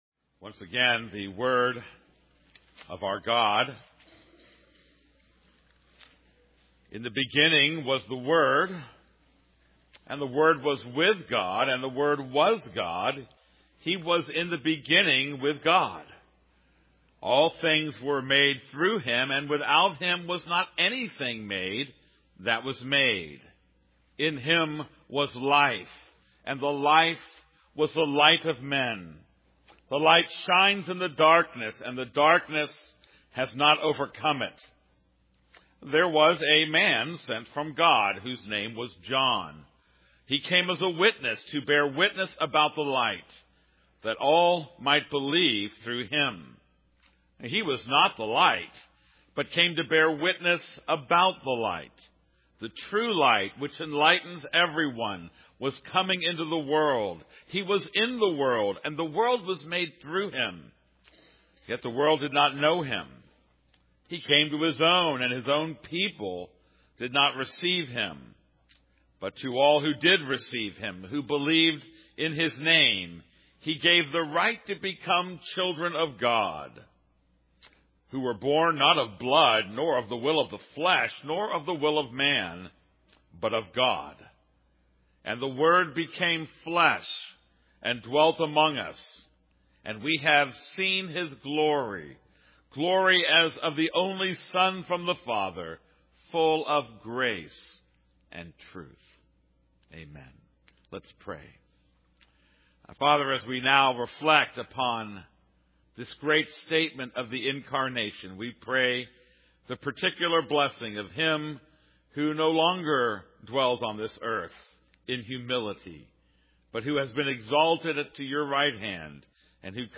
This is a sermon on John 1:1-14.